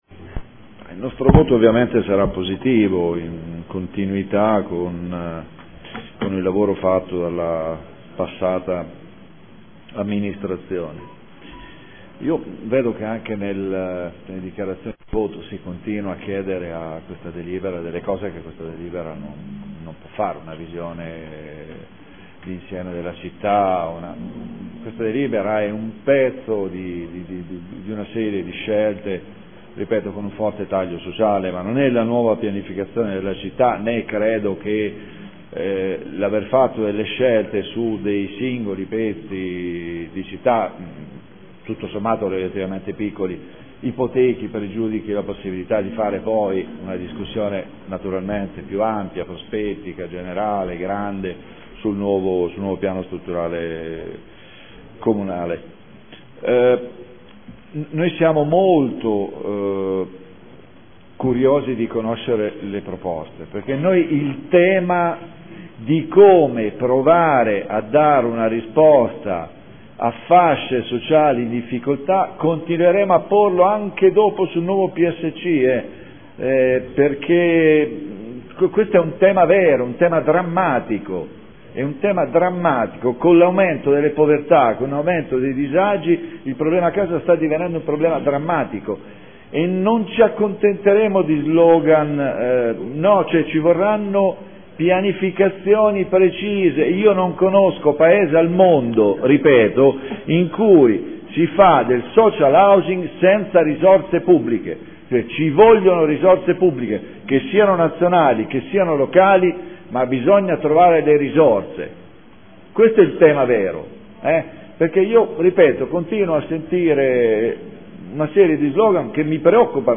Seduta del 13/11/2014 Dichiarazione di voto.